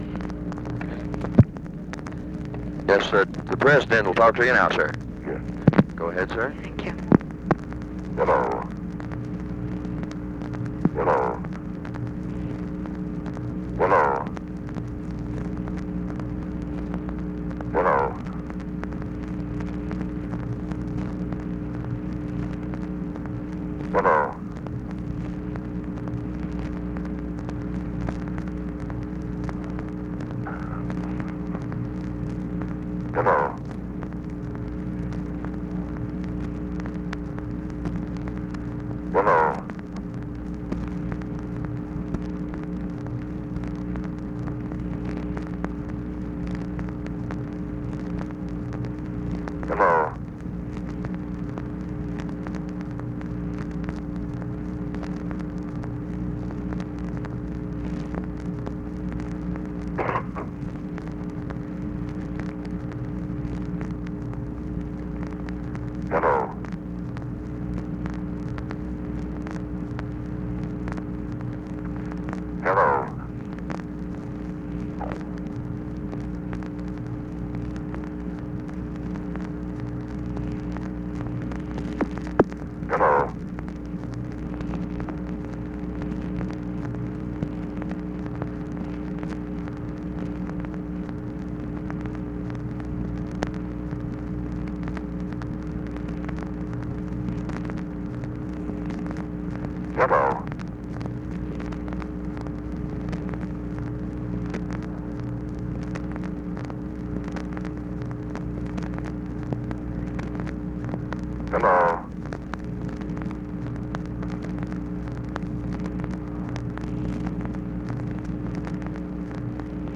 UNIDENTIFIED MALE TELLS DIRKSEN LBJ WILL SPEAK TO HIM NOW BUT LBJ DOES NOT COME ON THE LINE; DIRKSEN REPEATS "HELLO" THROUGHOUT RECORDING
Conversation with UNIDENTIFIED MALE and EVERETT DIRKSEN, November 4, 1964